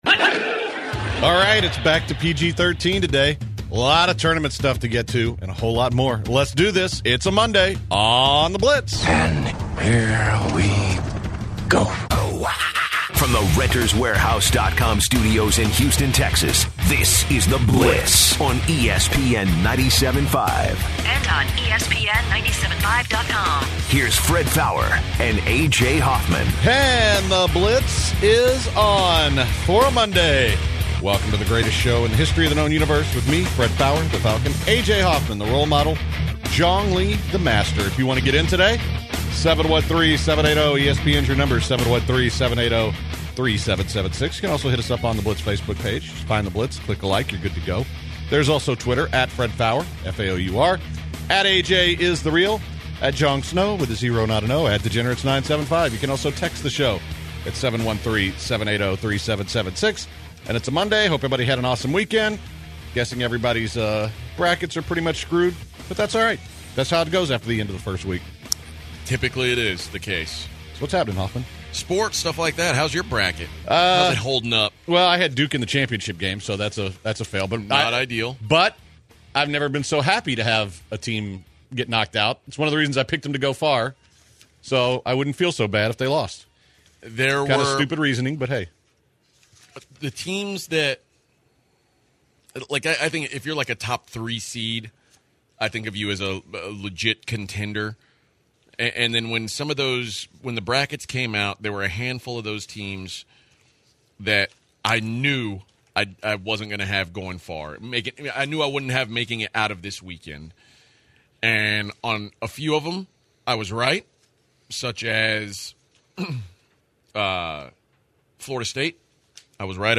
The guys are back in studio for this Monday after Spring Break. The guys talked the tourney and took some phone calls in the first hour.